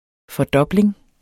Udtale [ fʌˈdʌbleŋ ]